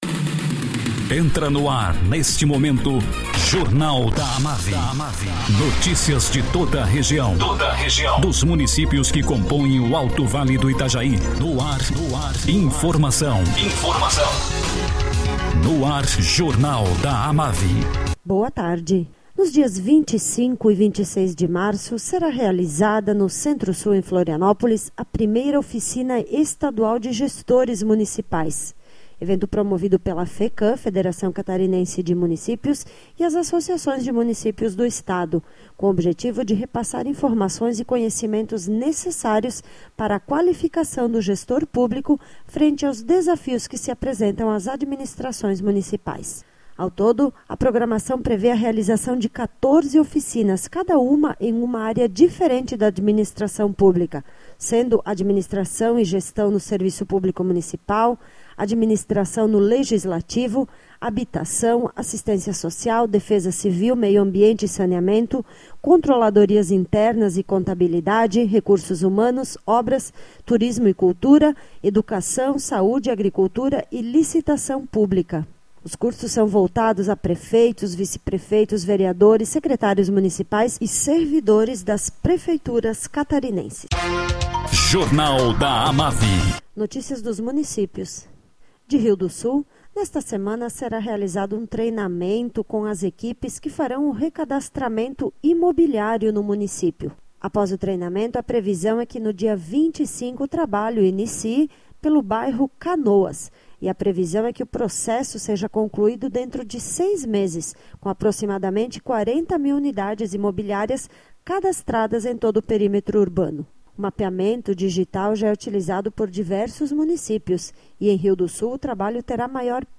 Prefeito municipal de Presidente Getúlio, Nilson Francisco Stainsack, fala sobre aquisição de veículos para transporte de pacientes e para o transporte escolar.